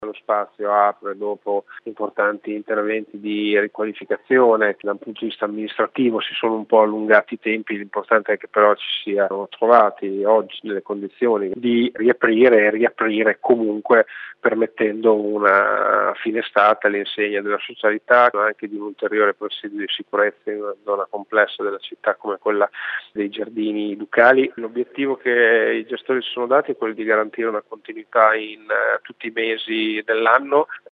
Un’apertura che ha subito qualche settimana di ritardo per via dei numerosi interventi effettuati nello stabile ospitato all’interno della Palazzina Vigarani come sottolinea l’assessore Andrea Bortolamasi